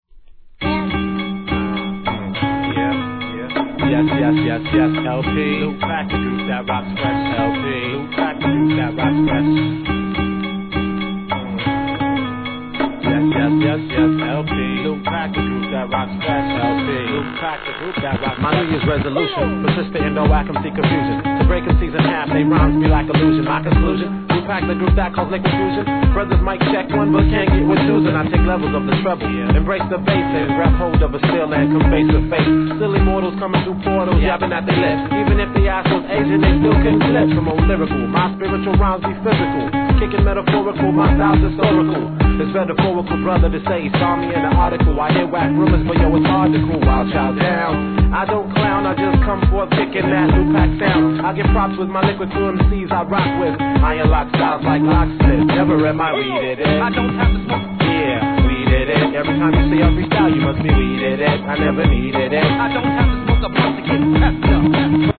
HIP HOP/R&B
美しいストリング・ハープ・ループを使った